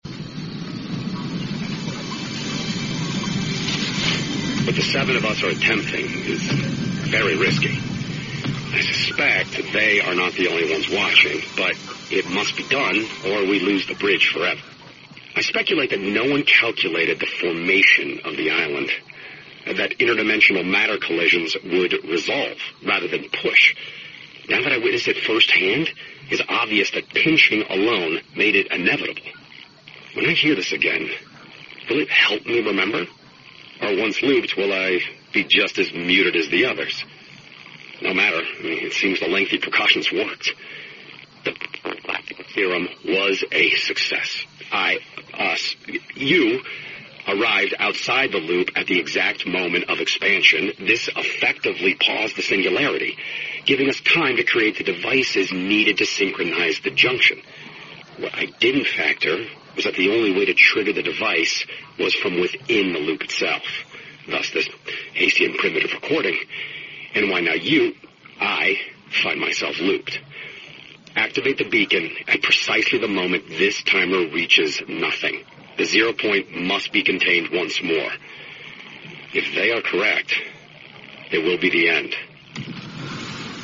FortniteSeasonXVisitorRecording.mp3